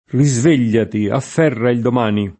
risveglio [ ri @ v % l’l’o ], ‑gli